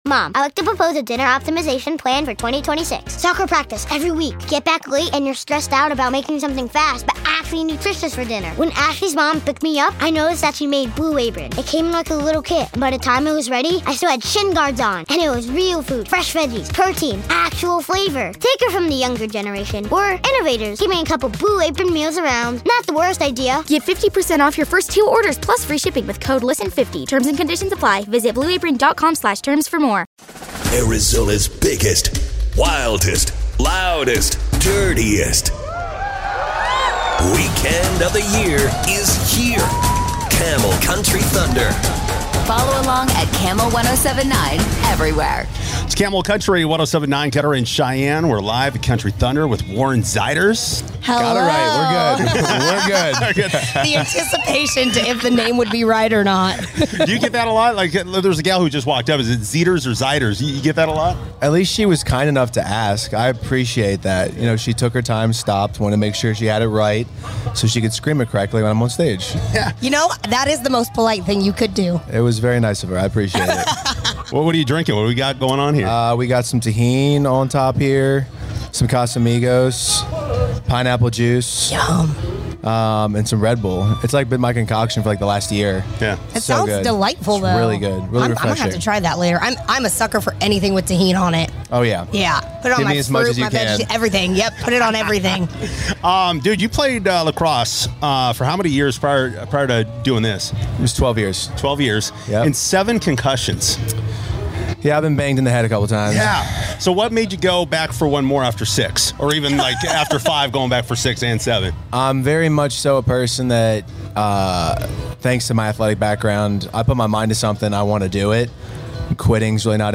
Warren Zeiders stopped by the Gila River Country Club at Country Thunder and we talked lacrosse, fitness, drama, new music & more!